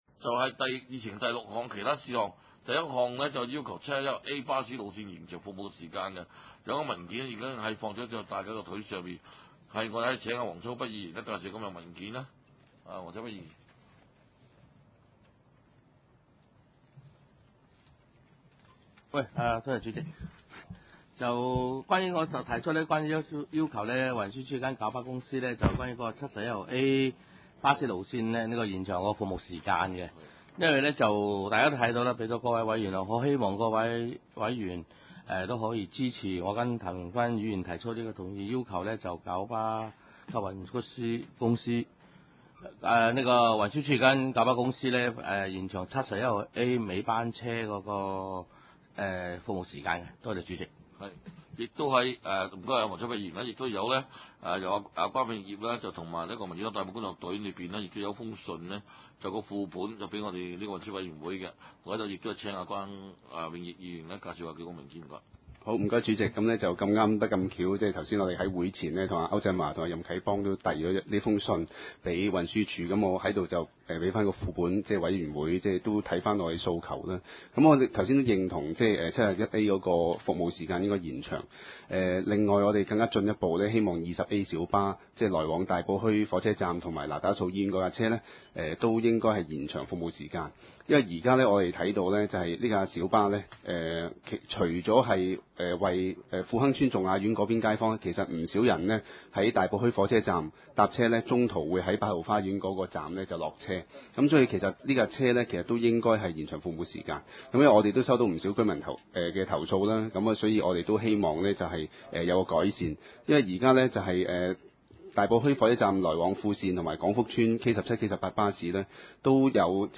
地點：大埔區議會秘書處會議室